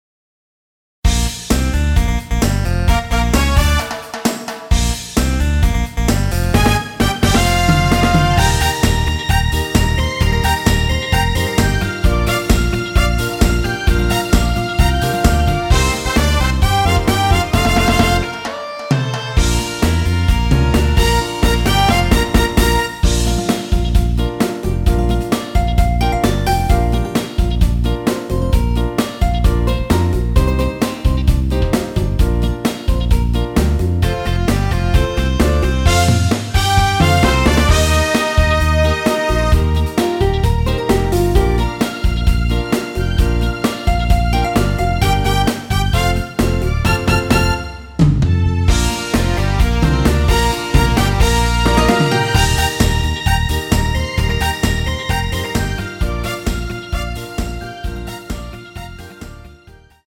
여성분이 부르실수 있는 키로 제작 하였습니다.
Bb
앞부분30초, 뒷부분30초씩 편집해서 올려 드리고 있습니다.